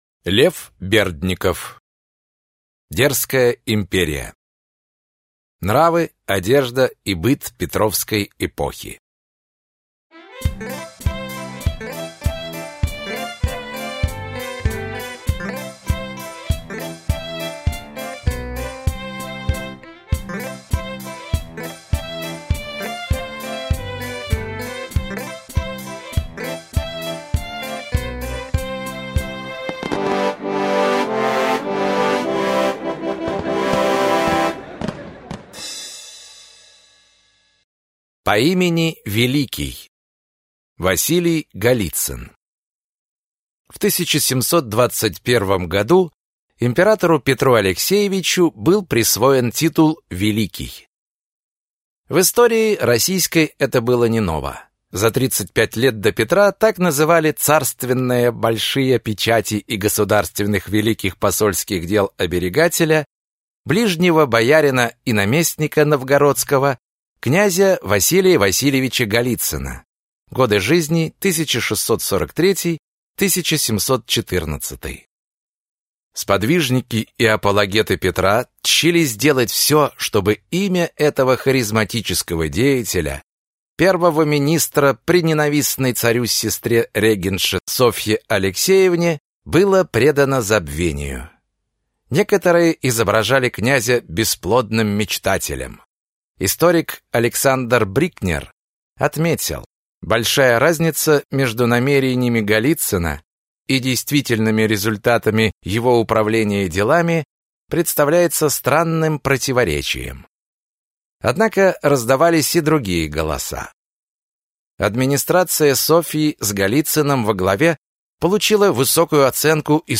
Аудиокнига Дерзкая империя. Нравы, одежда и быт Петровской эпохи | Библиотека аудиокниг